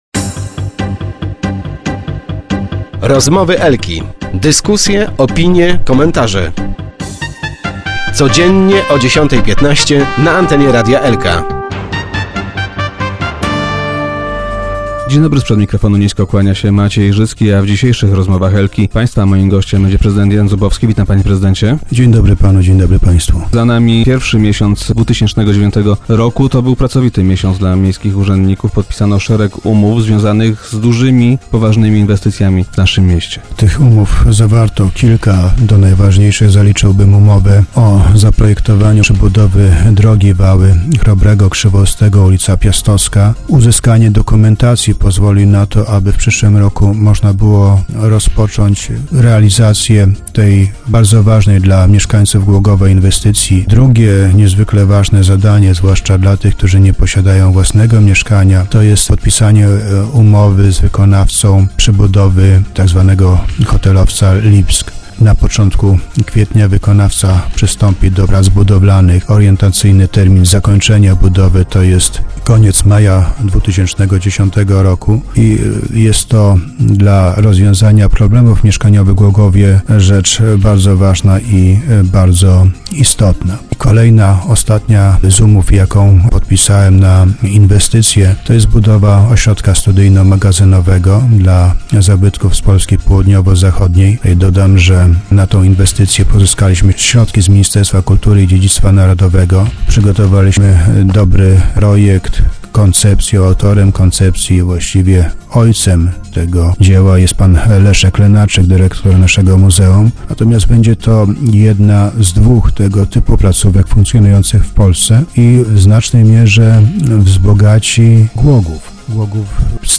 O kryzysie dyskutowano także podczas kongresu Prawa i Sprawiedliwości, w którym uczestniczył także prezydent Jan Zubowski, gość dzisiejszych Rozmów Elki. Jak zapowiedział prezydent - możliwe, że w związku z kryzysem, zweryfikowany zostanie Wieloletni Plan Inwestycyjny.